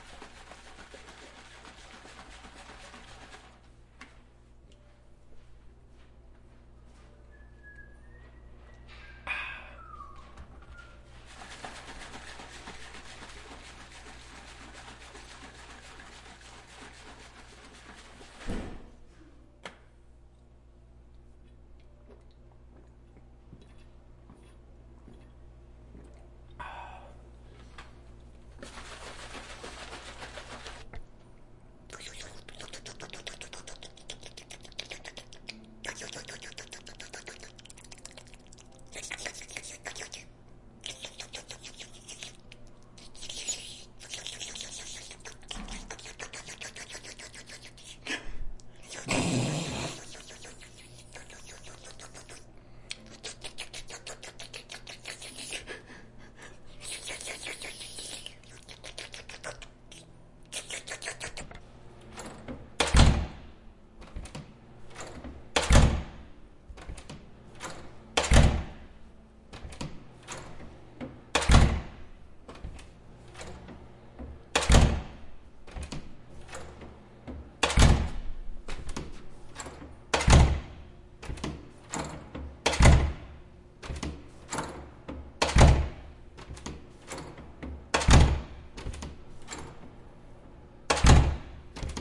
声景项目
描述：听起来更响